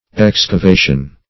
Excavation \Ex`ca*va"tion\, n. [L. excavatio: cf. F.